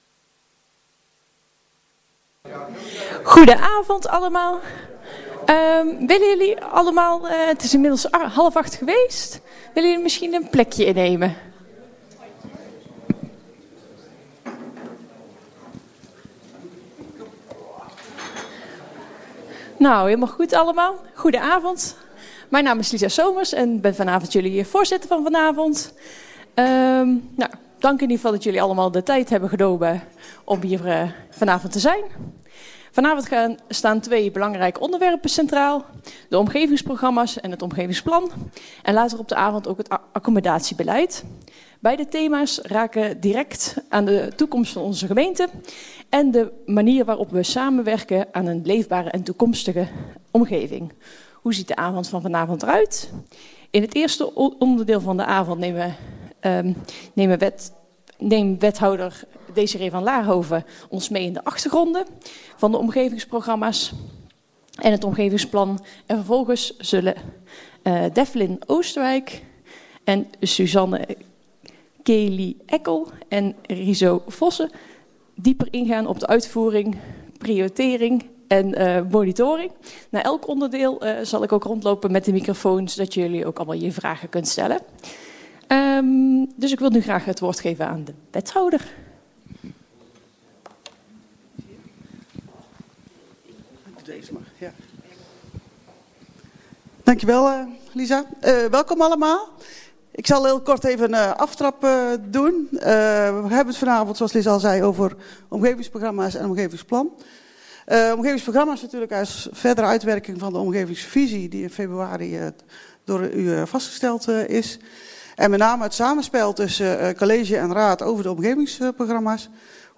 Locatie Raadzaal Boxtel
Geluidsopname informatie-uitwisselingbijeenkomst